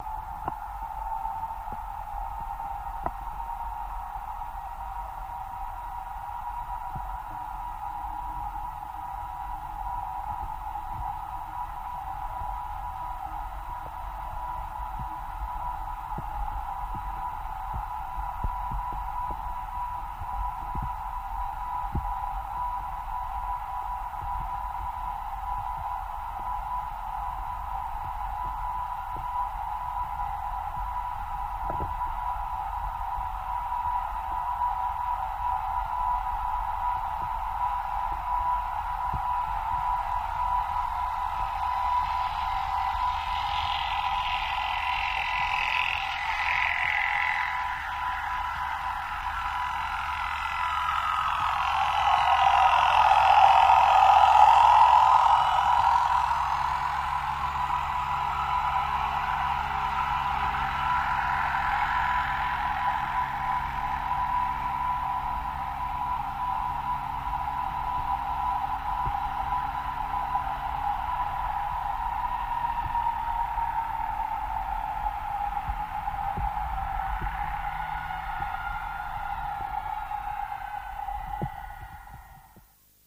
Torpedos
Torpedo Jet By